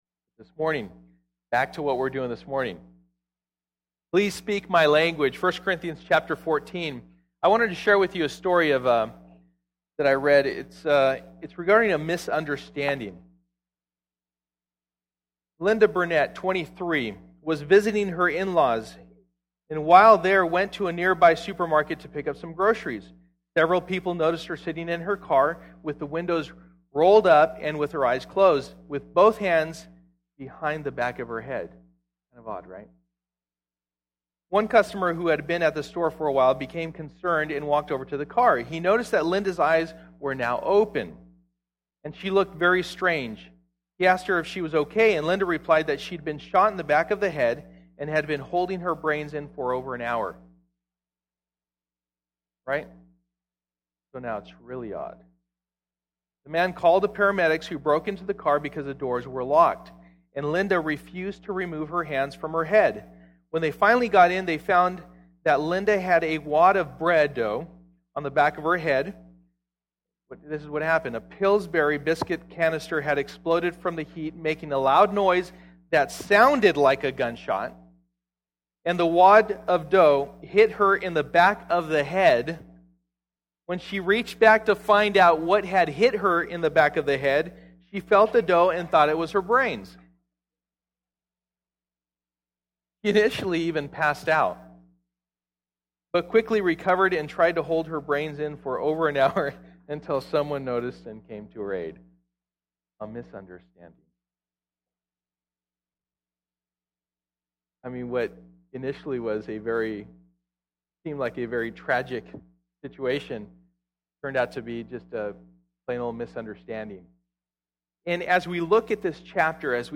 Passage: 1 Corinthians 14:1-25 Service: Sunday Morning